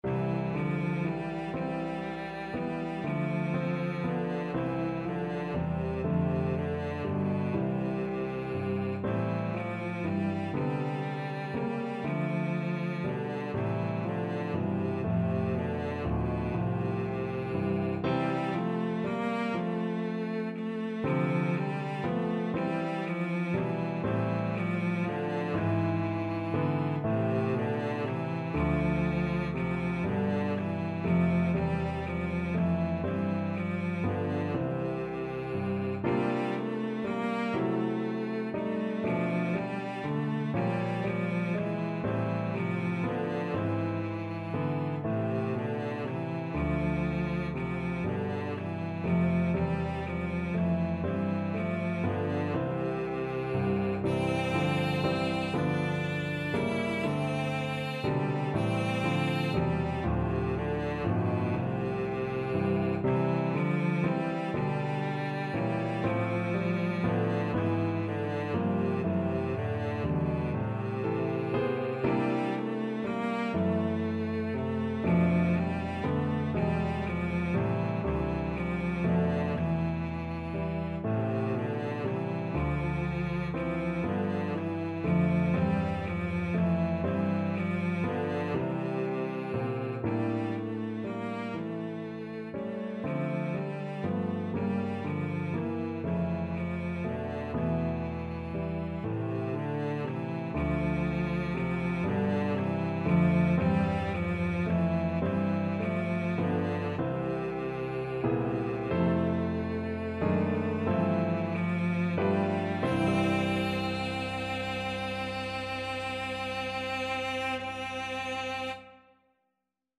9/4 (View more 9/4 Music)
=120 Andante tranquillo
B3-E5
Classical (View more Classical Cello Music)